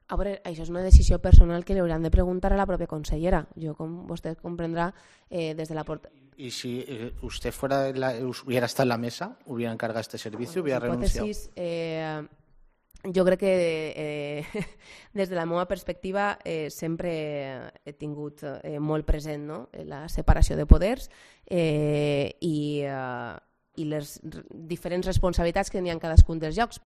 Así se ha pronunciado Mas en la rueda de prensa posterior al Pleno del Consell, en la que ha indicado que "respeta las decisiones políticas" de cada uno y que la permanencia de Bravo en el Consell es una decisión que debe tomar la propia consellera en base a "un apoyo o no jurídico que pueda existir".